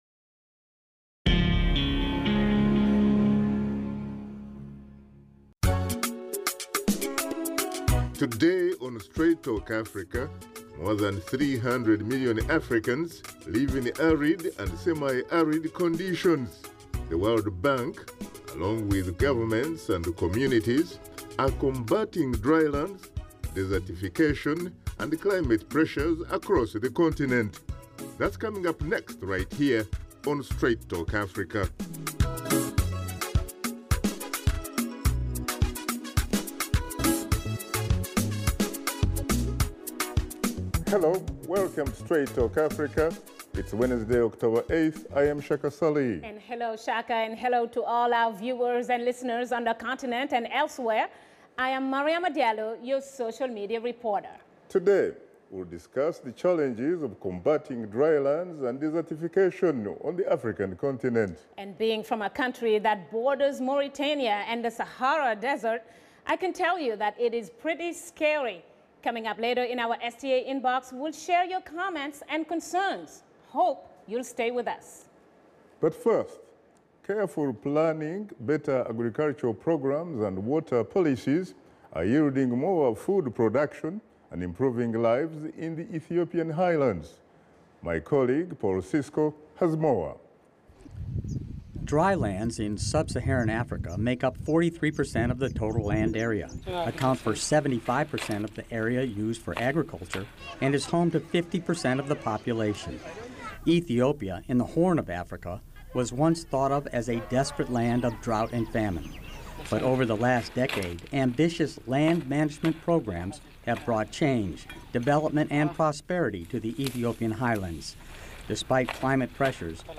Host Shaka Ssali and his guests discuss what African governments can do combat the devastating impact of droughts, floods and other natural disasters in Africa.